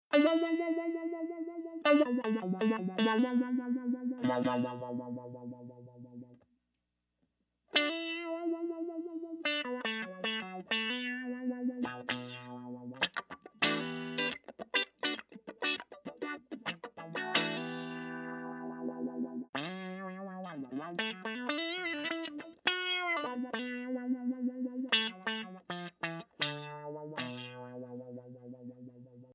Some playing in Combo – both attack trigger and clock trigger.
Choppy Mode – this clip starts with just clock mode, and then it is switched to combo to add extra attack sensitivie dynamics: